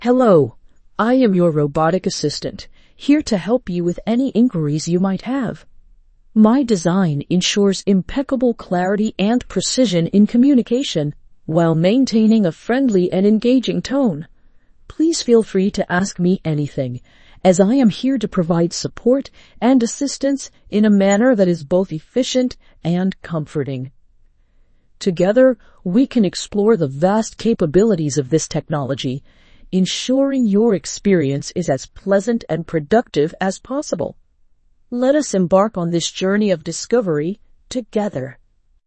Robot assistant with a friendly, slightly mechanical voice that speaks with perfect diction